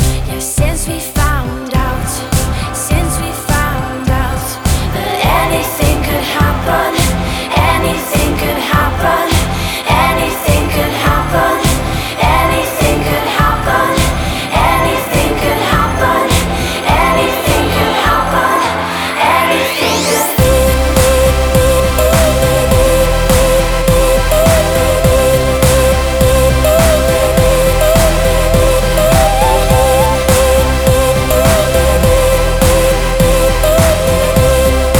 Жанр: Поп музыка / Рок / Танцевальные / Электроника